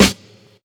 Snares
UY_SNR2.wav